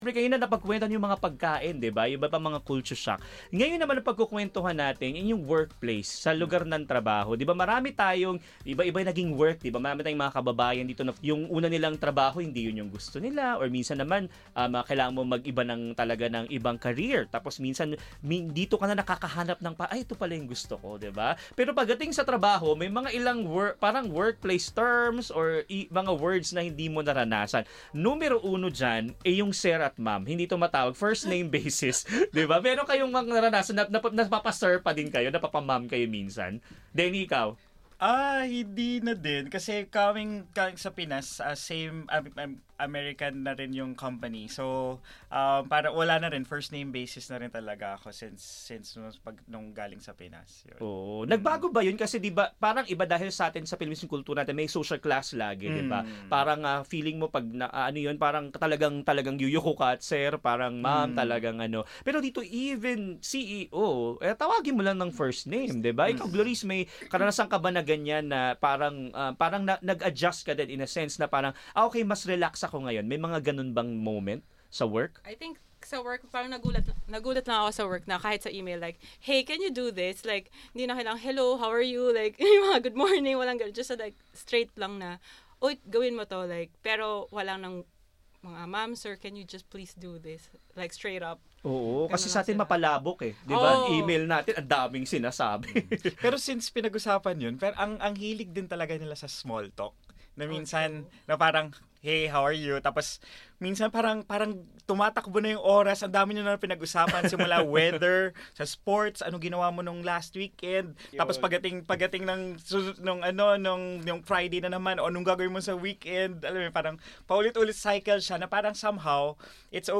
Some Filipinos shared their experiences of cultural adjustment and workplace culture in Australia.